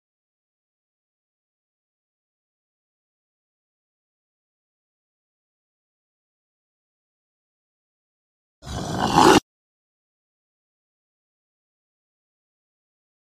dog bark sound effect sound effects free download